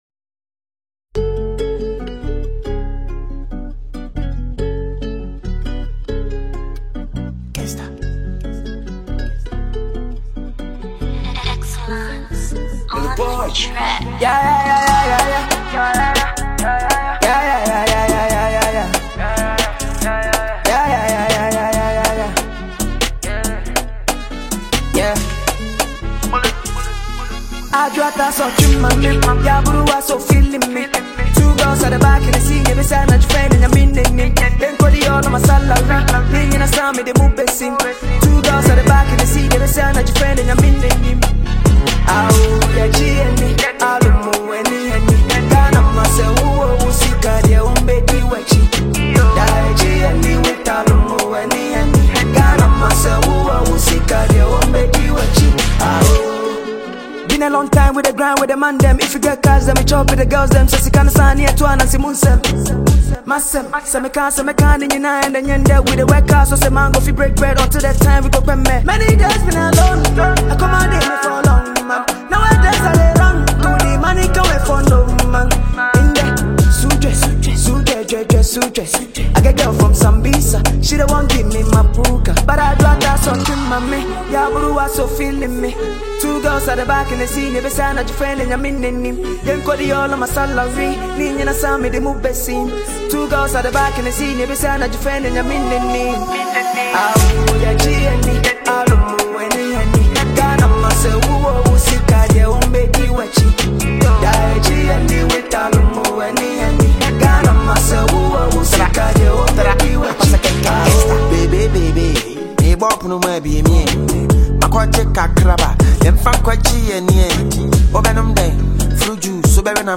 Ghana MusicMusic
street jam